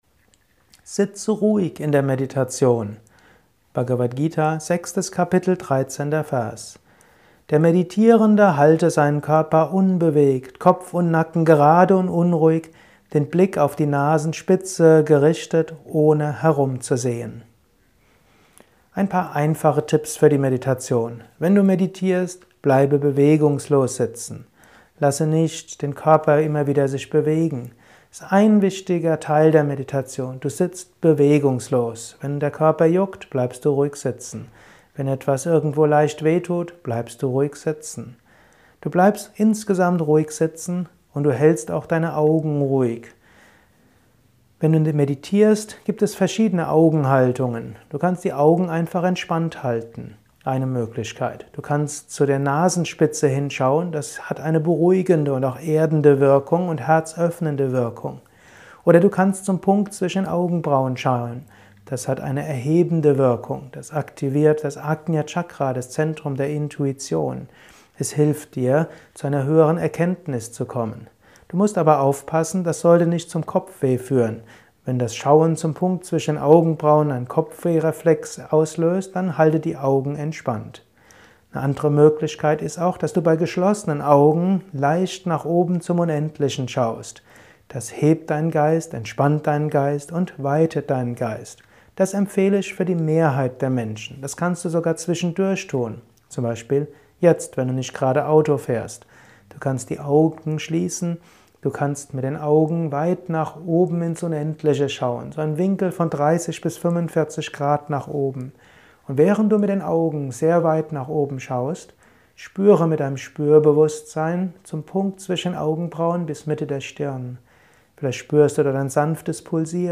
Kurzvortrag